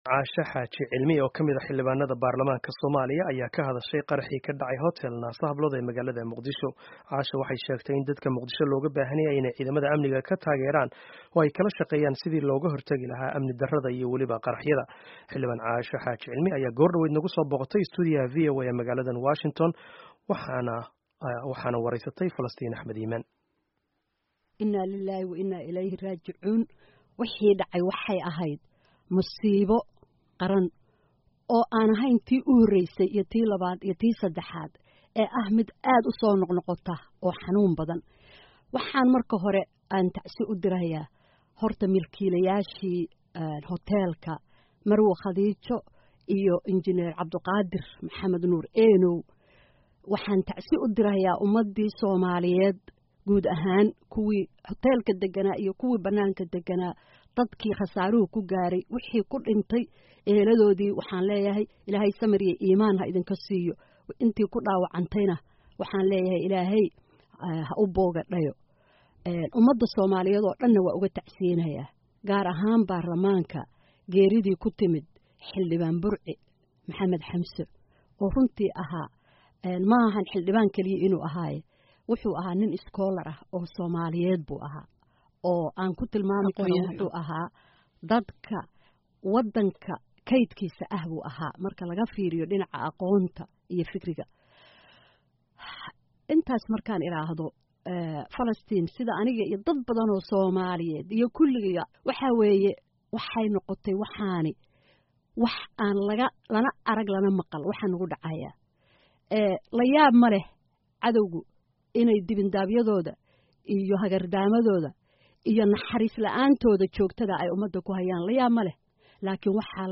Xildhibaan Caasha Xaaji Cilmi oo ka tirsan baarlamaanka Soomaaliya ayaa xarunta VOA nagu soo booqatay, waxayna wareysi ay VOA siisay kaga hadashay qaraxyada Muqdisho.
Waresyi: Xildhibaan Caasha Xaaji Cilmi